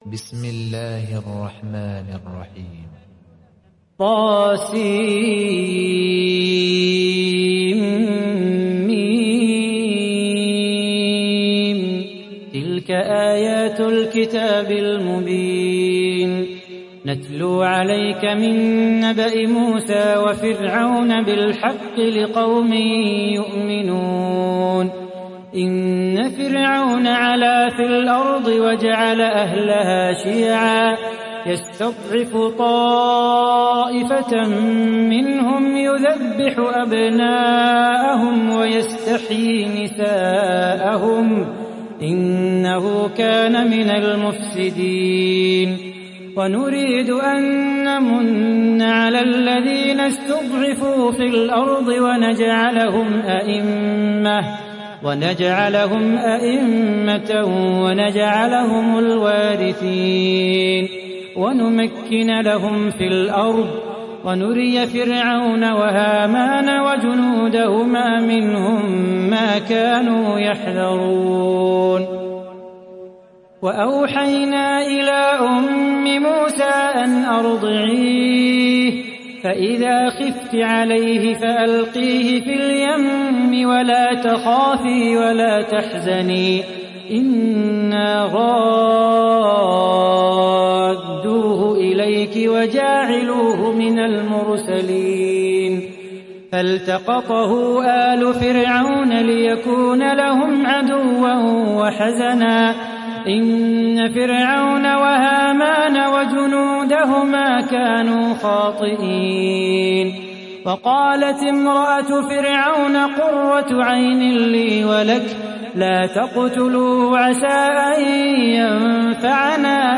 دانلود سوره القصص mp3 صلاح بو خاطر روایت حفص از عاصم, قرآن را دانلود کنید و گوش کن mp3 ، لینک مستقیم کامل